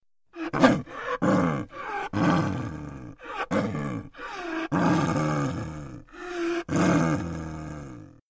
Звуки снежного барса
Рычание снежного барса